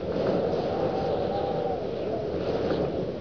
Index of /pub/quakerepo/fortress/sound/ambience
peakwind.wav